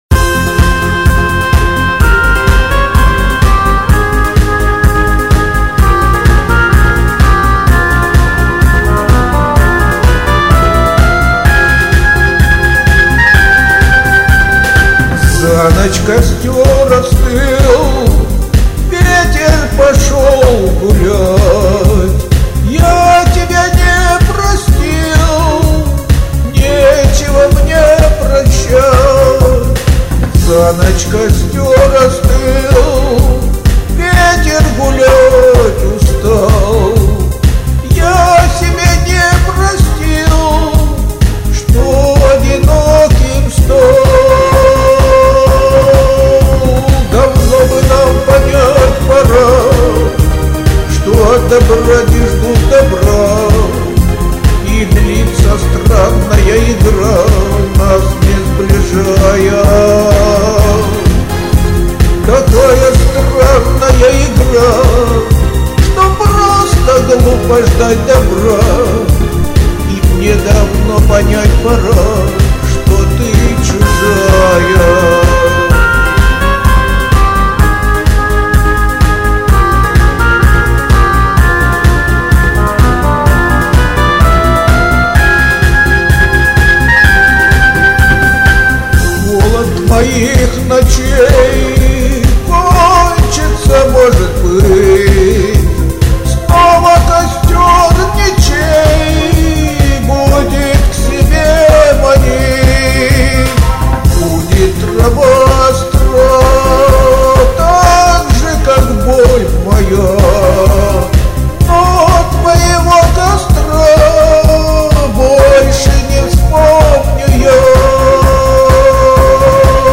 Музыкальный хостинг: /Шансон